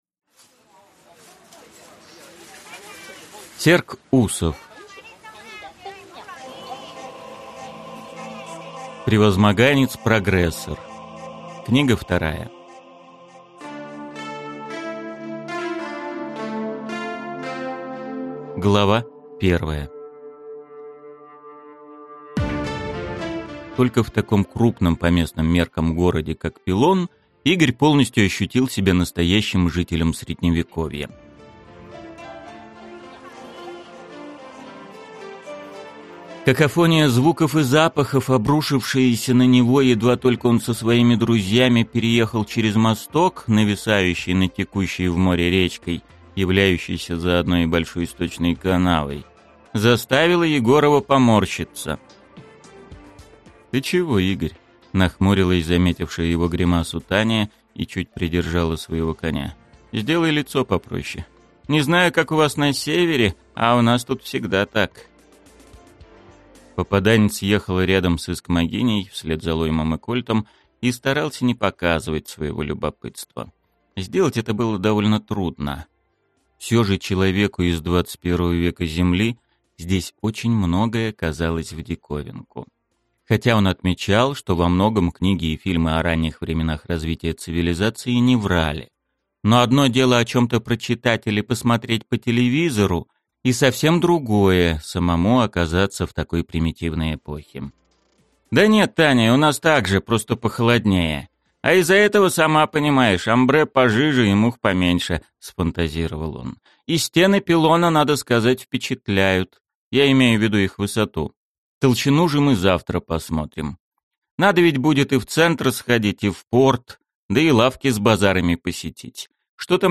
Аудиокнига Превозмоганец-прогрессор. Книга 2 | Библиотека аудиокниг